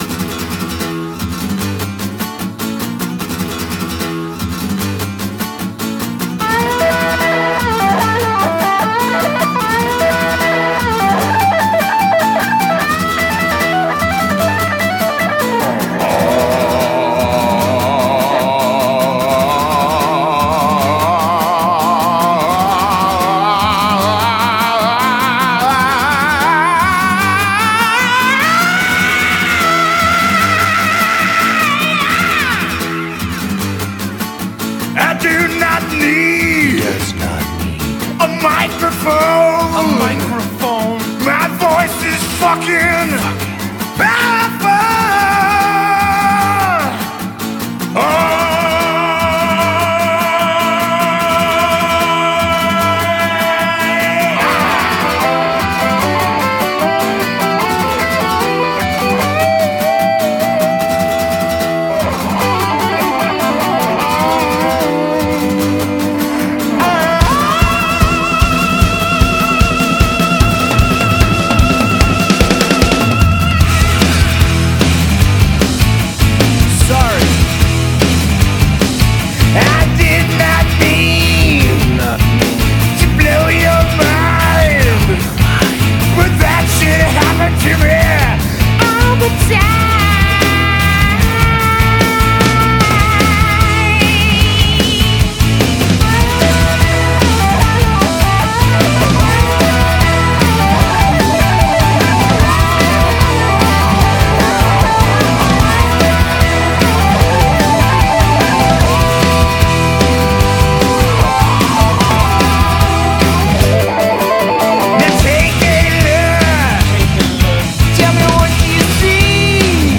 BPM150
Audio QualityMusic Cut